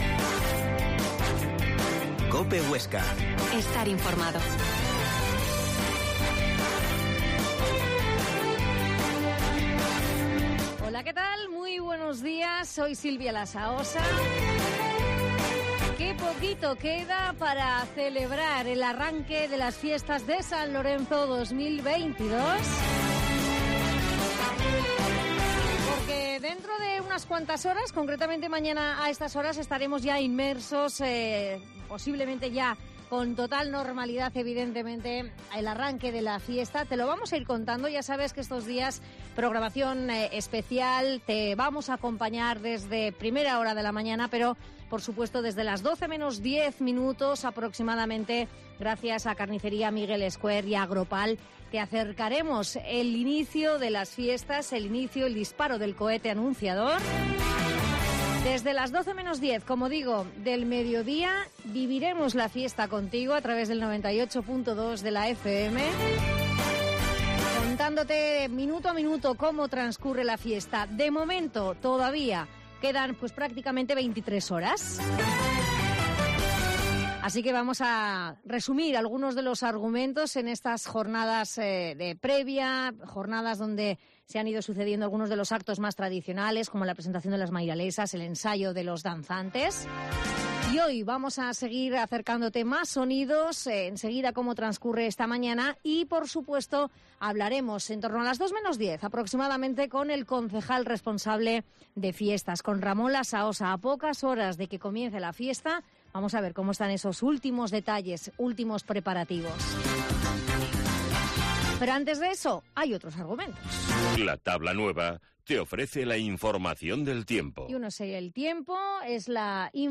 Herrera en COPE Huesca 12.50h Resumen informativo